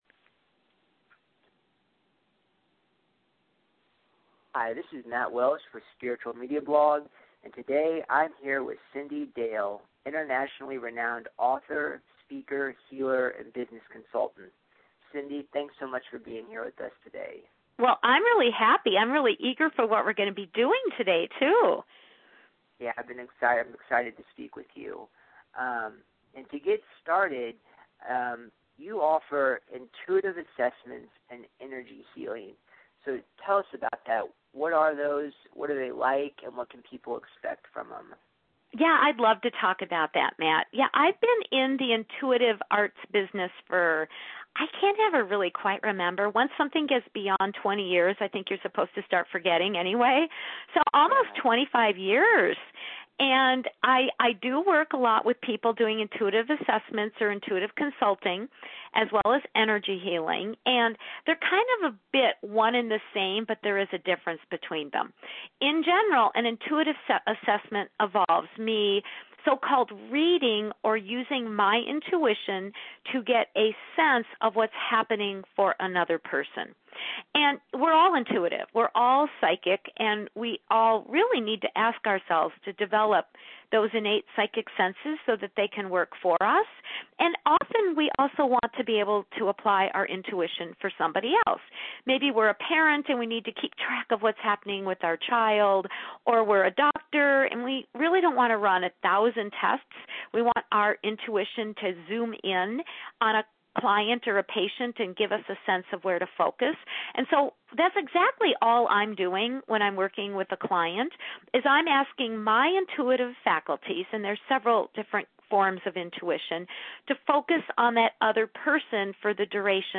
I recently had a chance to talk to her about her work.